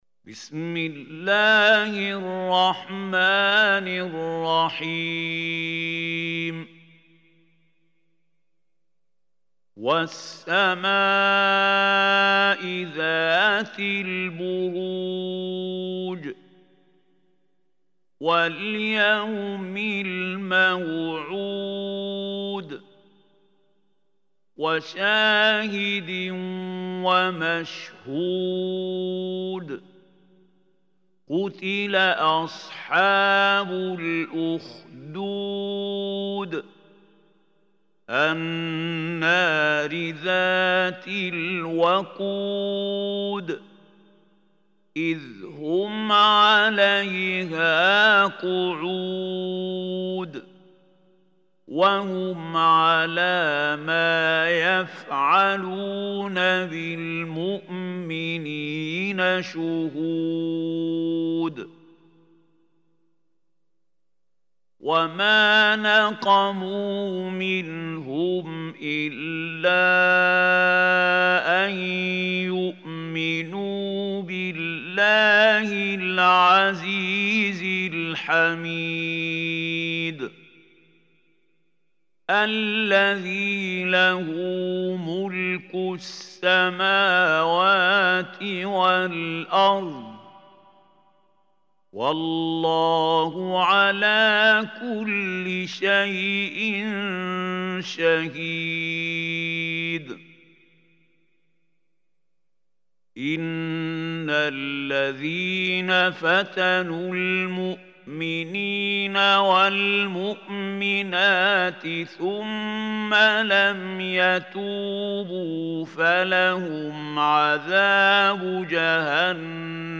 Surah Burooj MP3 Recitation Mahmoud Khalil Hussary
Surah Burooj is 85 surah of holy quran. Listen or play online mp3 tilawat / recitation in Arabic in the beautiful voice of Sheikh Mahmoud Khalil Hussary.